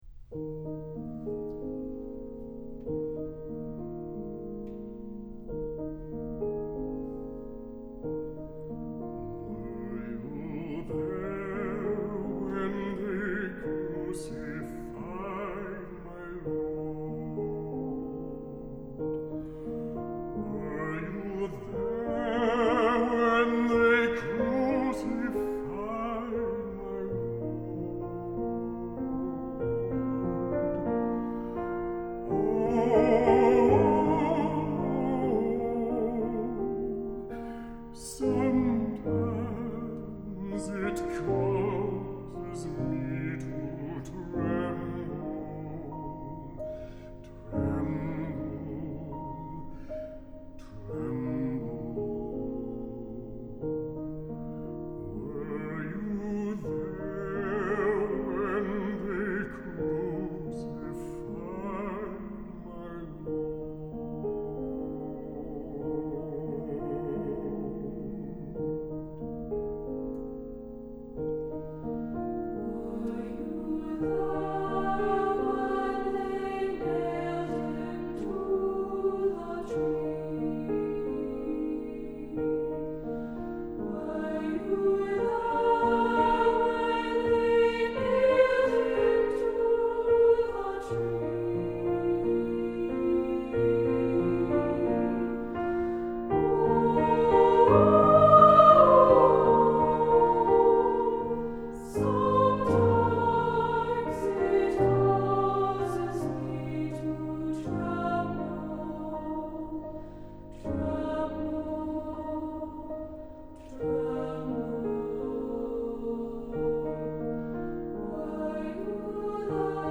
Composer: Spirituals
Voicing: SATB and Piano